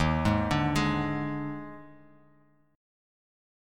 D#M7sus4 chord